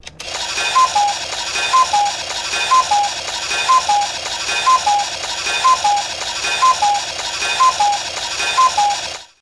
Coo Coo Clock | Sneak On The Lot